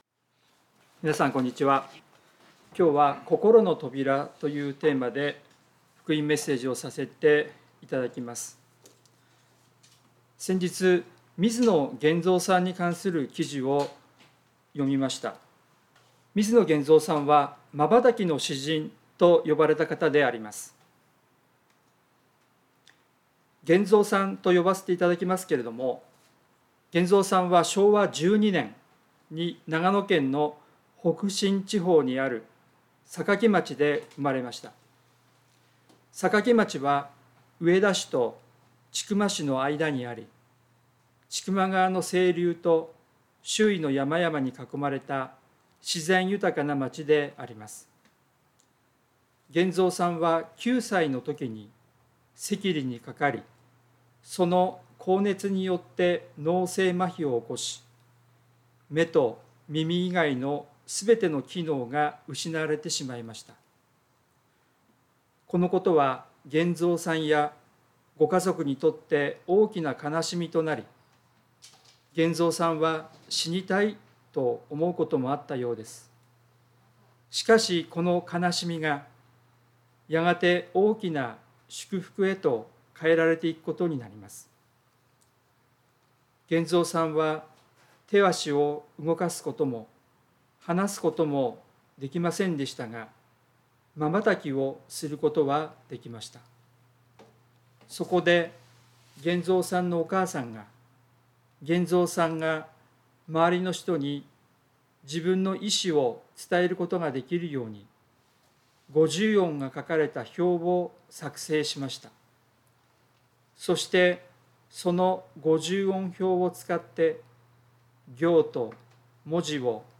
聖書メッセージ No.285